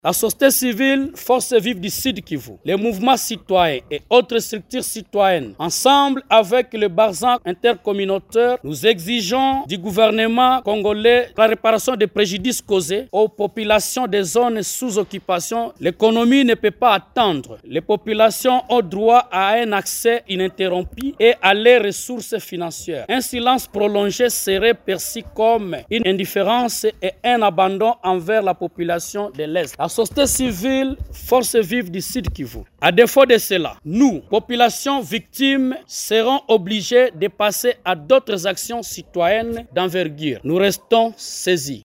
Un extrait de cette déclaration lu ici par